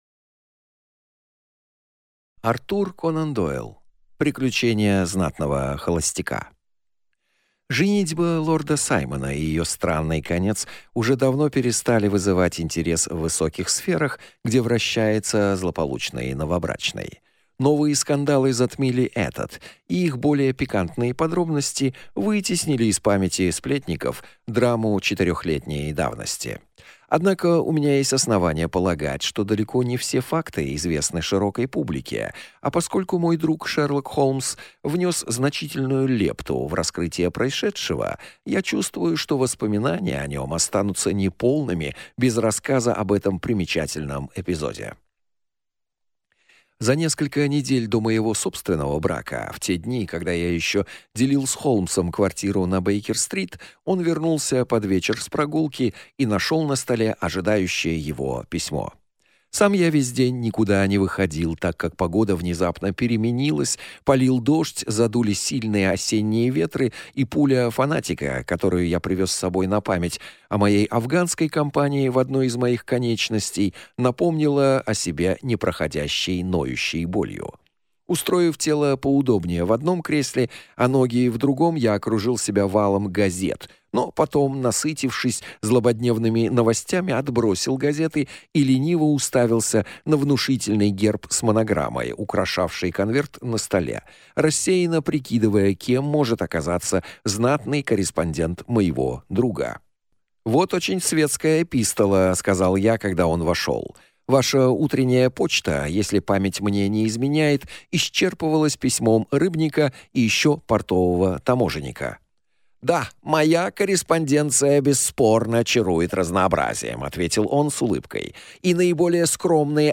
Аудиокнига Приключение знатного холостяка | Библиотека аудиокниг